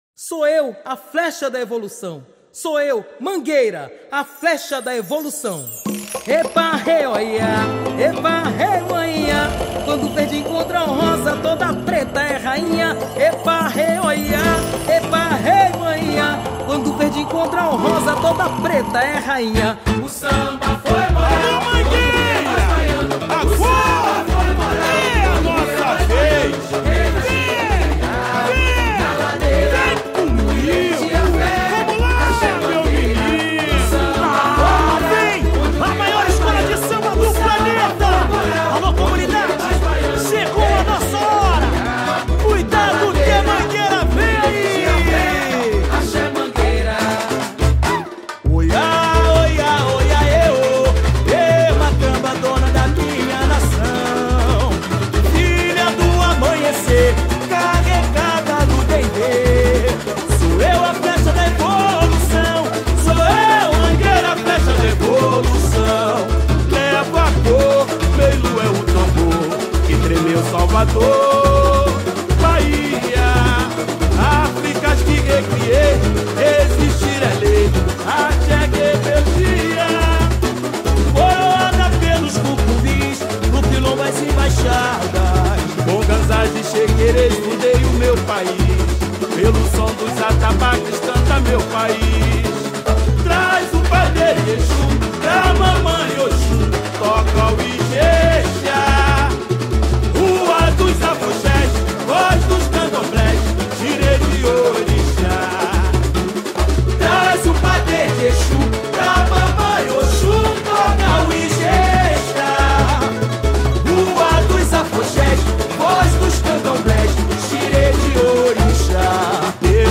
Samba Enredo 2023